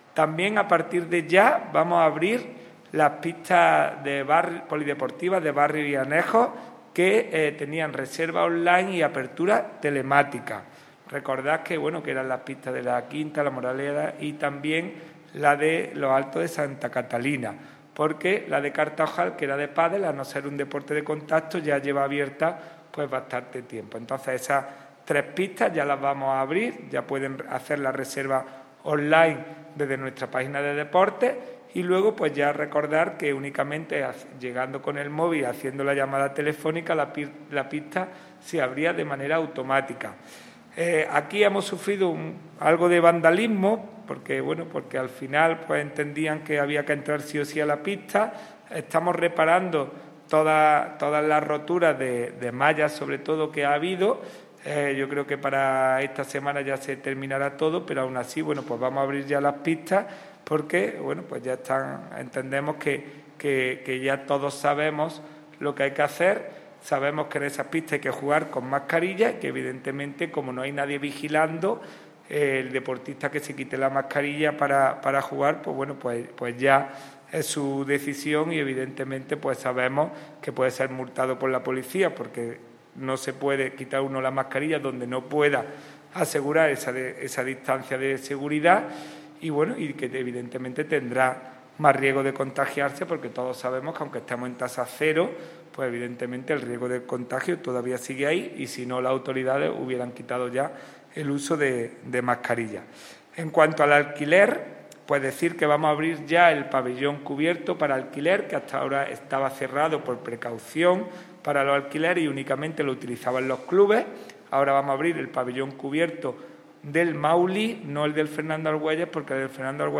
El teniente de alcalde delegado de Deportes del Ayuntamiento de Antequera, Juan Rosas, ha confirmado en rueda de prensa la entrada en vigor –a partir de este lunes 18 de octubre– de nuevas normas menos restrictivas en el uso y participación en actividades deportivas municipales que se celebran en recintos como el Pabellón Fernando Argüelles o la Piscina Cubierta Municipal, así como la puesta en disposición para su reserva y alquiler de varias pistas deportivas destinadas a la práctica de deportes en equipo.
Cortes de voz